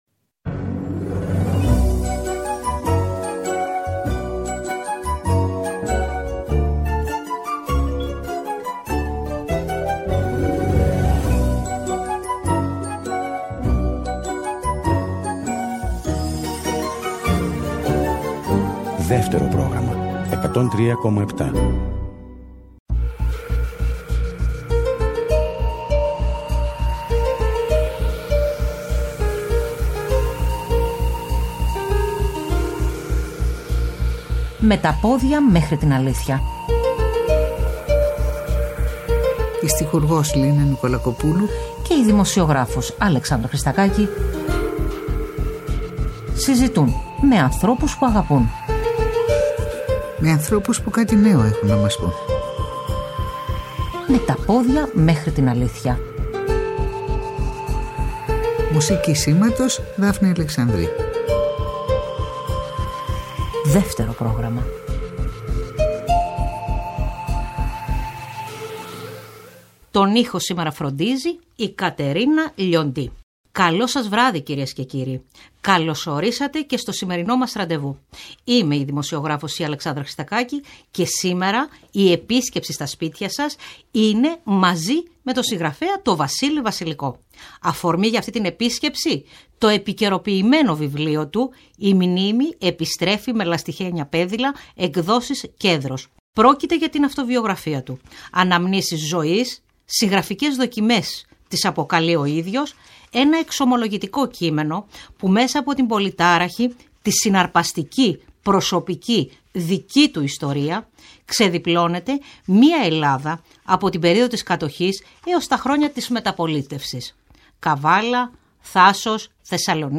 Ο συγγραφέας Βασίλης Βασιλικός καλεσμένος στις 25 Ιούνιου 2023 στην εκπομπή ” Με τα πόδια μέχρι την αλήθεια“. Θυμάται τα σημαντικότερα γεγονότα της ζωής του καθώς συμπληρώνει εννέα δεκαετίες. Αφορμή η αυτοβιογραφία του με τίτλο ” Η μνήμη επιστρέψει με λαστιχένια πέδιλα” εκδόσεις Καστανιώτης.